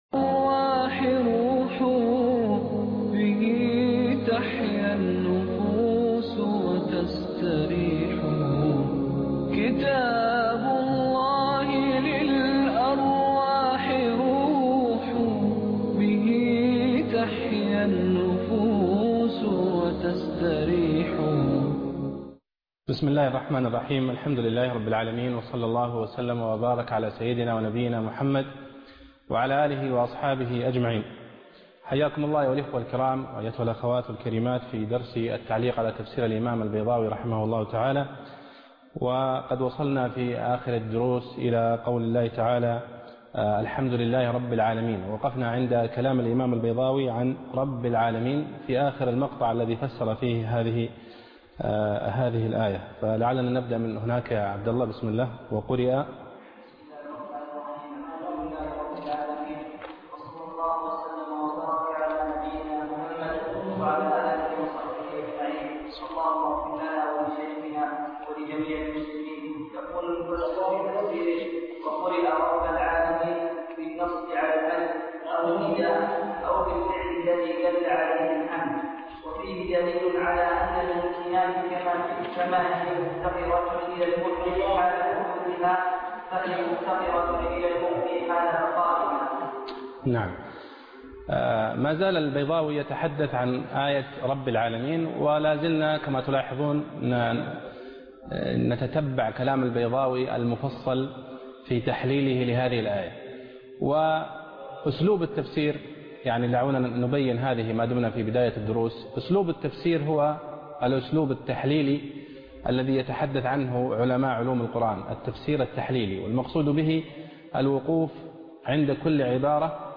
الدرس السادس (29/11/2013)